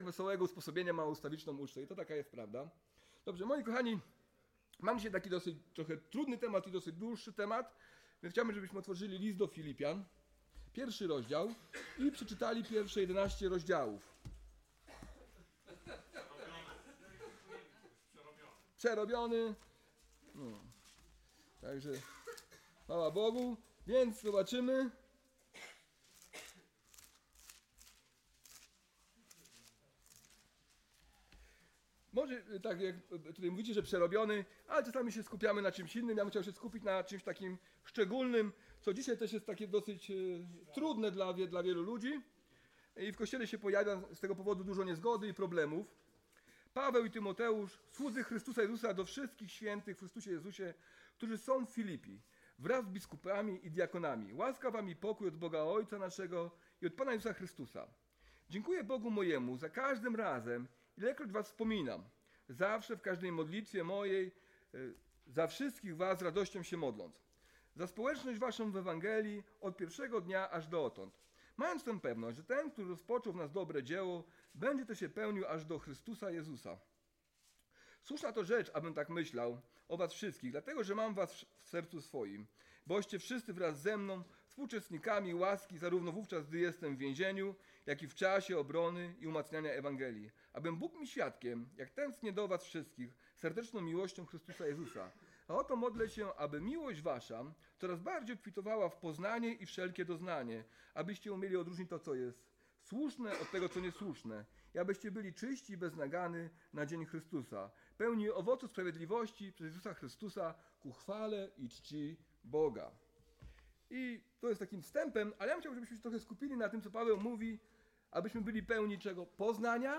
Obydwoje opowiedzieli świadectwo swojego małżeństwa.